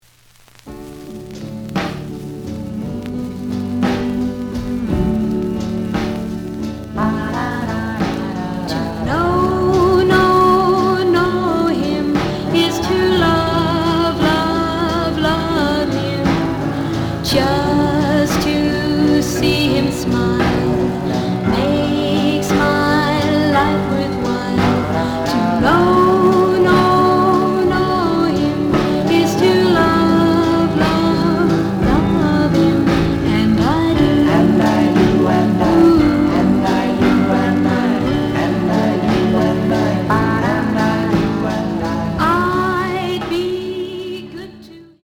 The audio sample is recorded from the actual item.
●Genre: Rock / Pop
Slight noise on both sides.